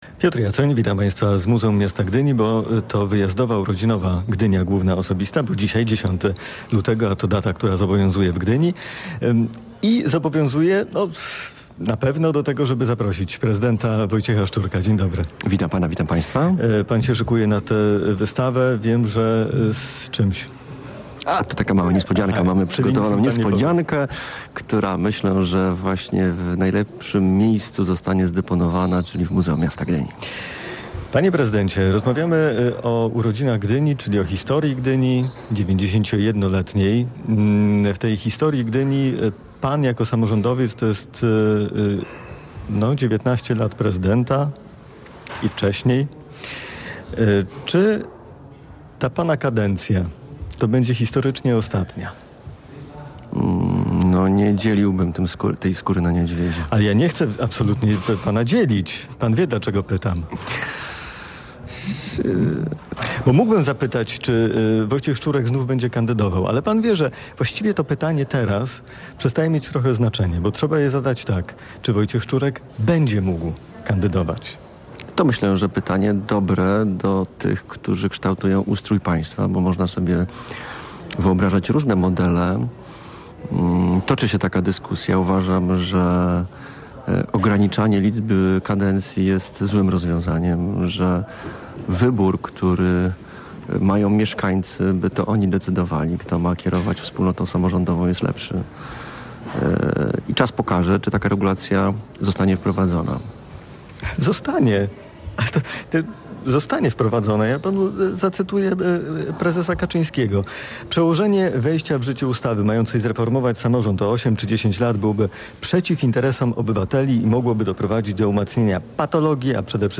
– Sytuacja, w której mieszkańcy decydują kto ma kierować wspólnotą samorządową jest lepsza – podkreślał prezydent w rozmowie